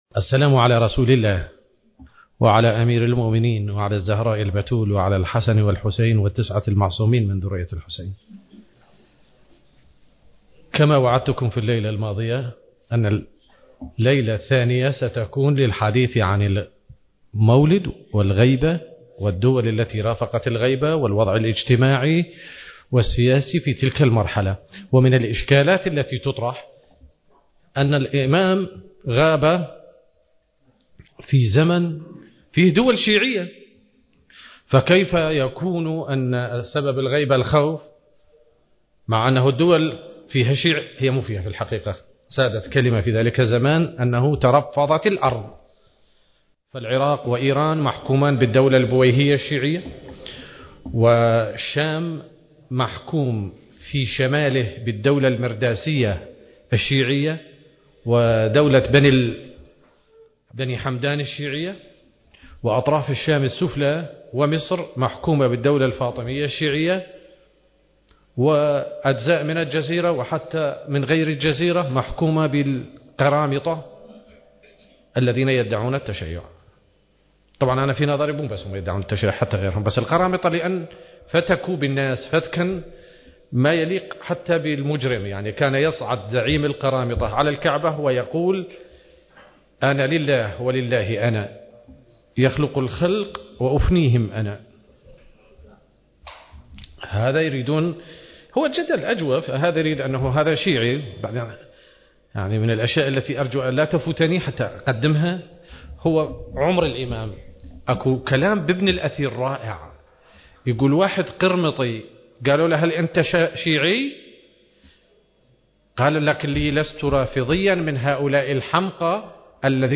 المكان: الحسينية الحيدرية / الكاظمية المقدسة التاريخ: 2022